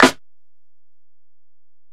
Snare (36).wav